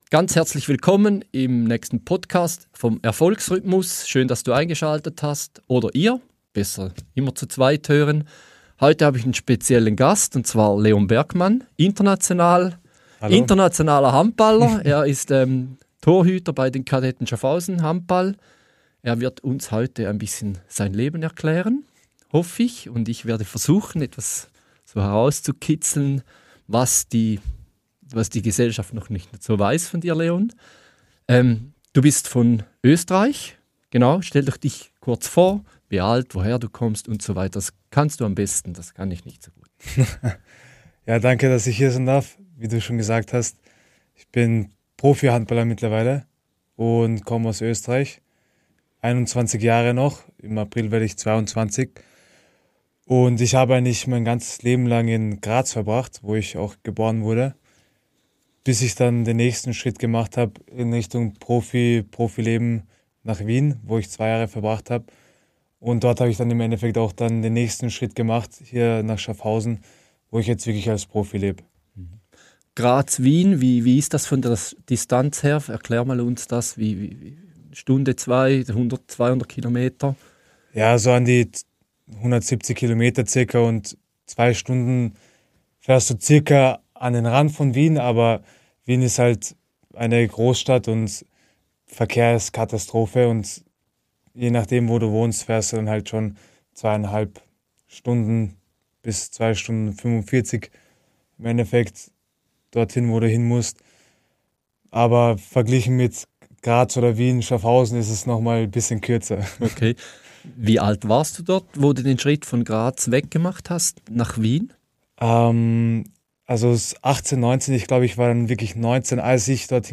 Wir tauchen ein in Themen wie Identität, Selbstwert, den Umgang mit Hochs und Tiefs, heitere Gelassenheit an der Spitze – und warum echte Erfüllung nicht im Ziel, sondern im Weg entsteht. Ein ehrliches, inspirierendes Gespräch über Leistung, Lebendigkeit und die Kunst, ganz im Moment zu sein.